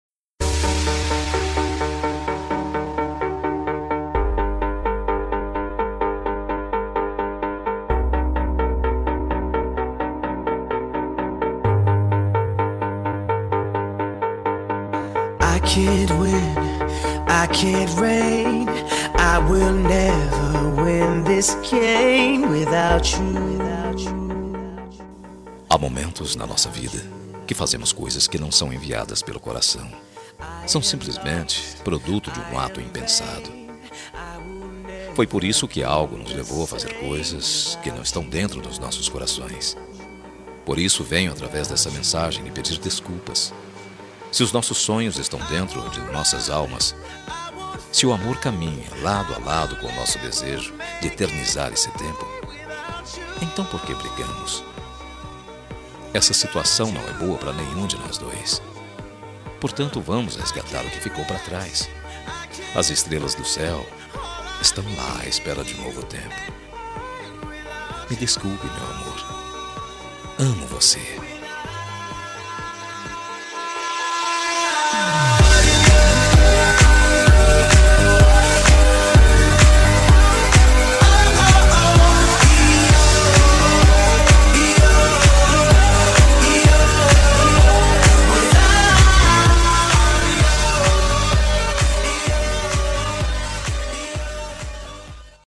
Telemensagem de Desculpas – Voz Masculina – Cód: 202041